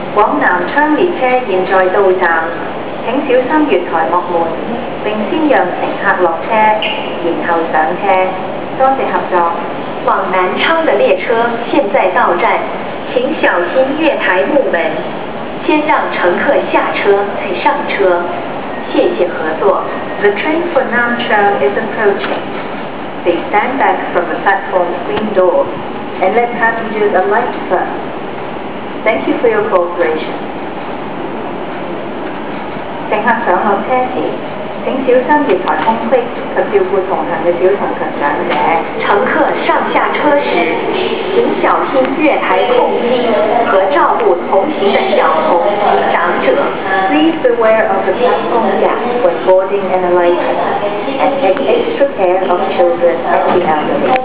【錄音】西鐵（綫）往南昌廣播